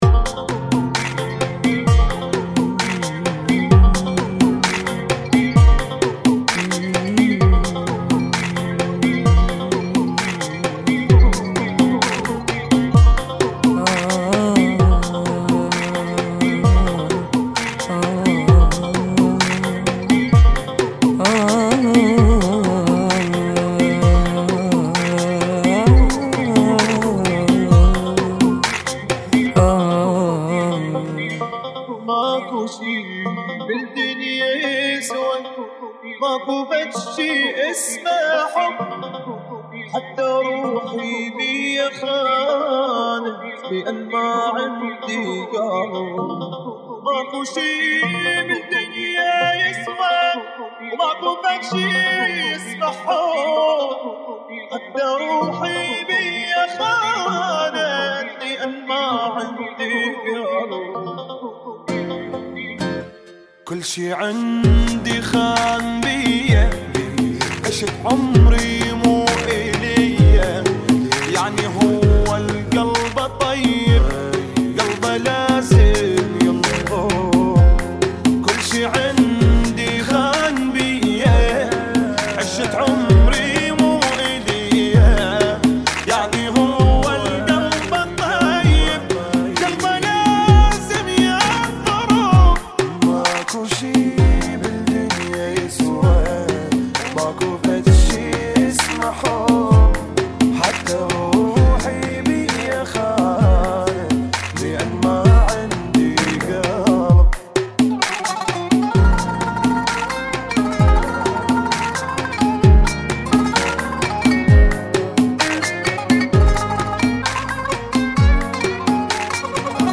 Funky [ 65 Bpm ]